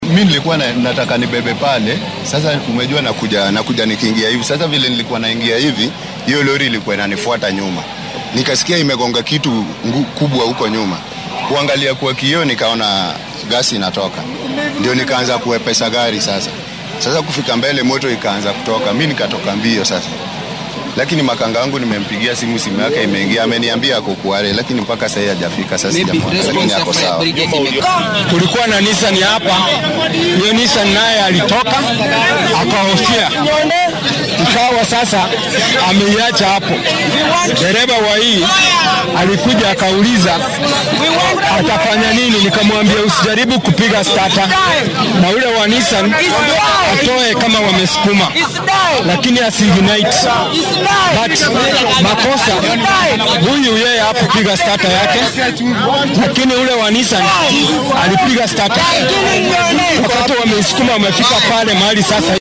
Qaar ka mid ah goobjoogayaasha dhacdadaasi ayaa warbaahinta la hadlay.
Goobjoogayaasha-qaraxa-gaaska.mp3